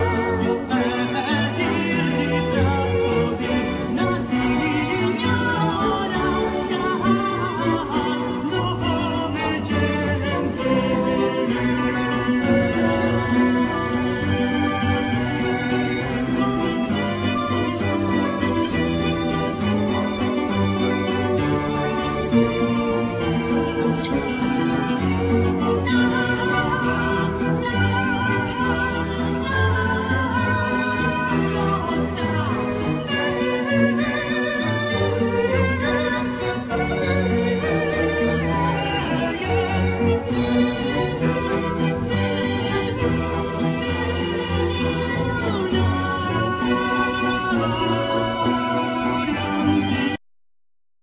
String Quintet:
Keyboard,Orchestrations
Vocals
Drum,Programming
Double-Bass
Hurdy-gurdy,Violin
Celtic harp